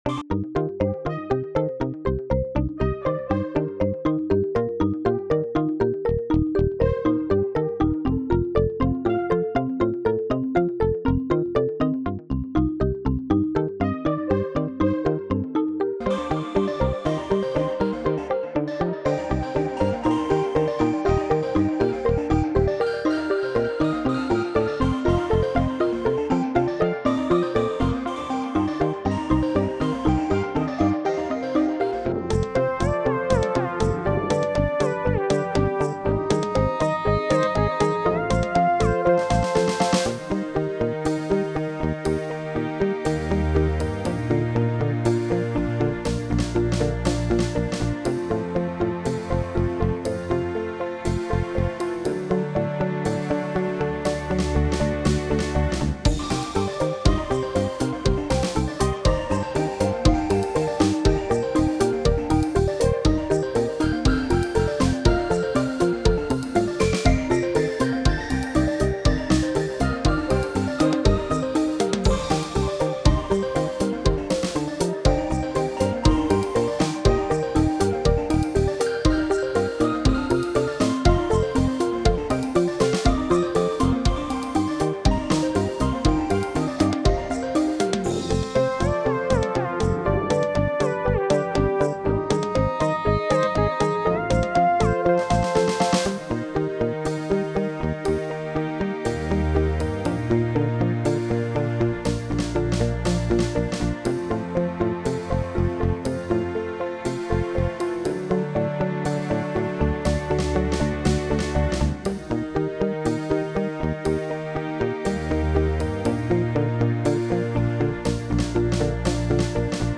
A few different instruments from the Instrument Rack, with arpeggios of different lengths. The one on the right side is delayed an eighth note so the quarter notes are interleaved.